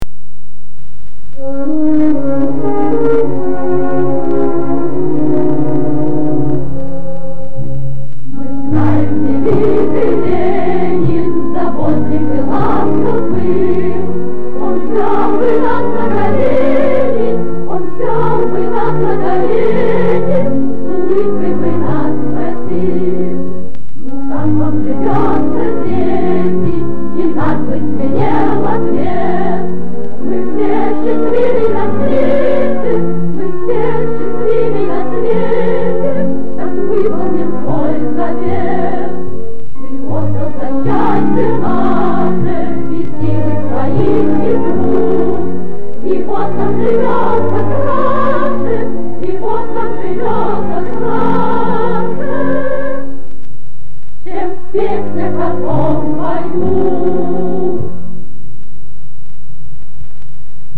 Описание: Очень короткая песенка